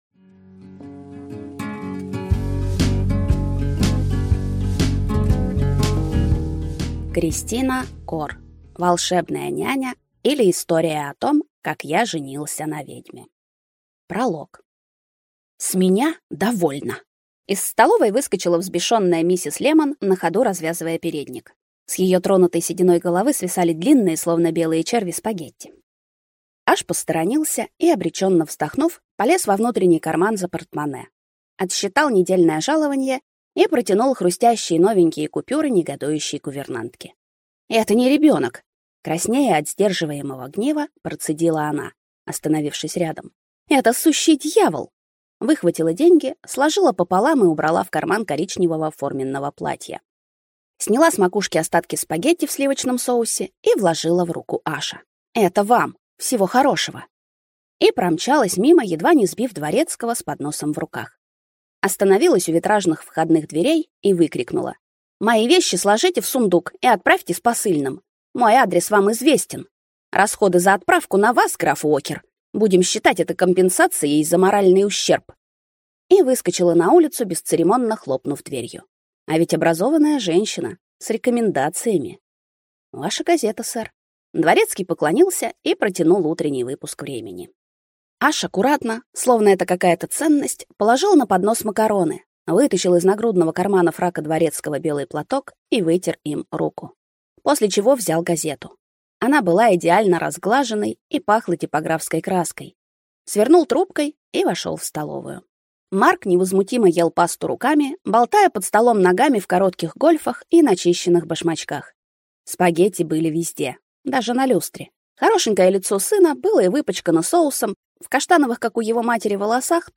Аудиокнига Волшебная няня, или История о том, как я женился на ведьме | Библиотека аудиокниг